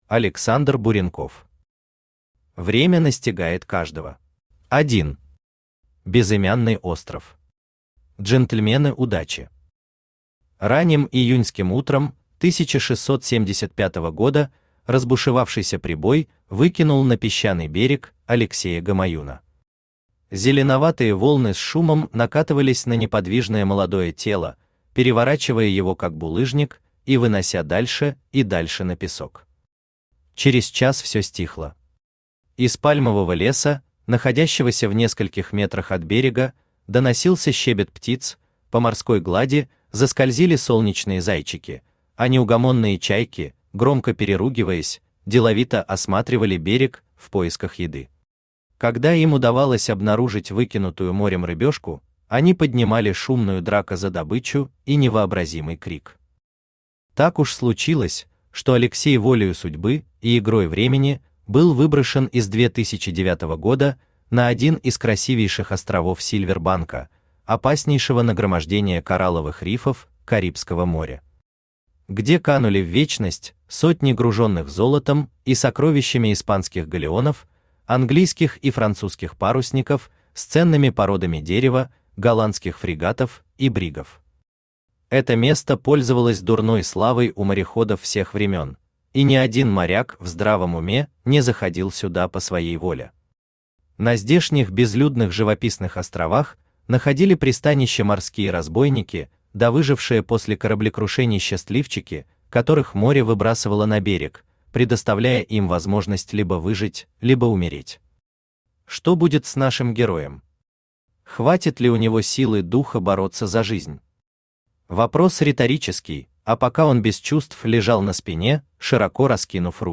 Аудиокнига Время настигает каждого | Библиотека аудиокниг
Aудиокнига Время настигает каждого Автор Александр Васильевич Буренков Читает аудиокнигу Авточтец ЛитРес.